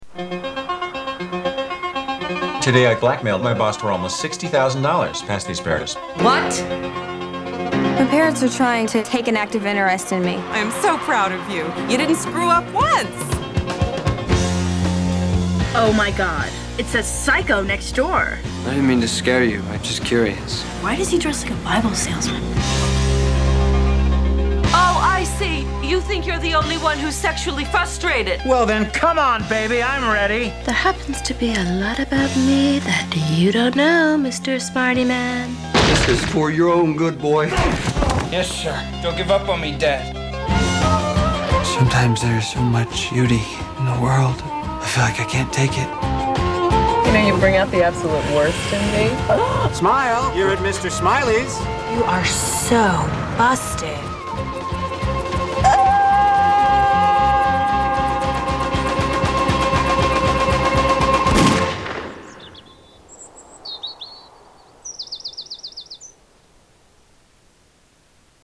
Theatrical Trailor 2